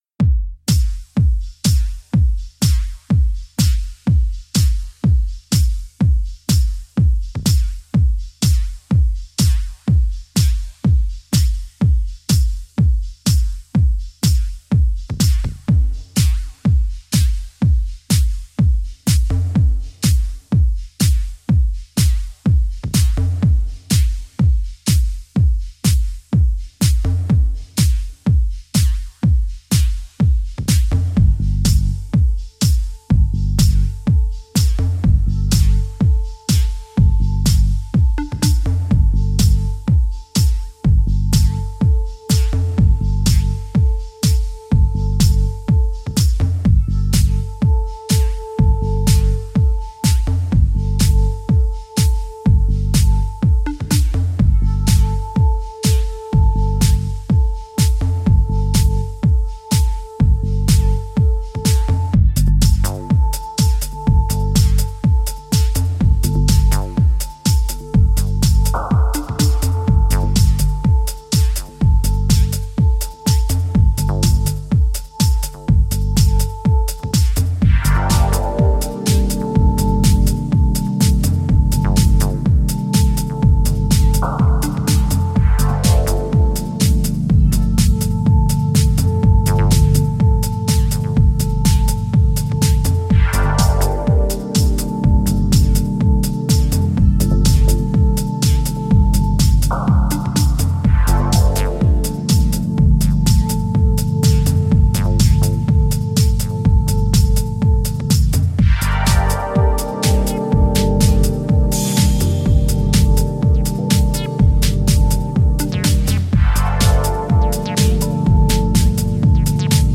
これが中々に素晴らしい内容で、アナログな質感のグルーヴや浮遊コードを駆使したディープ・ハウス群を展開しています。